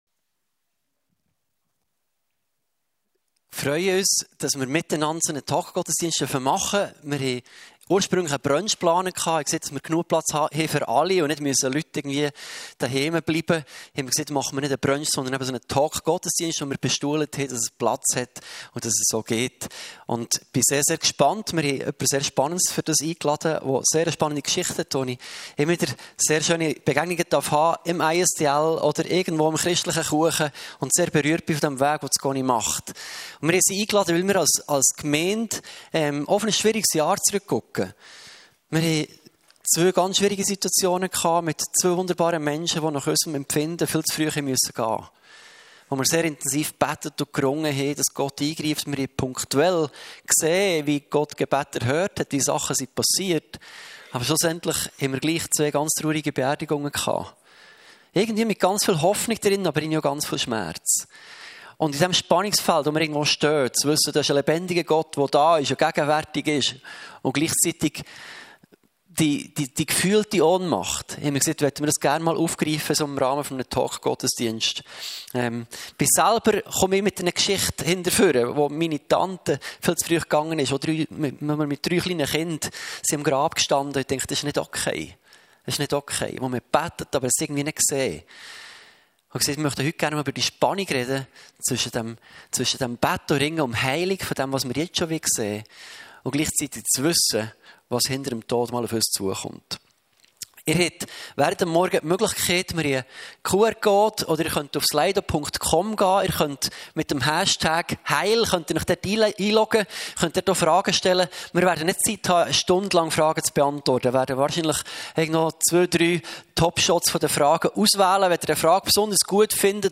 Eine Auswahl an Predigten der FMG Frutigen (Schweiz).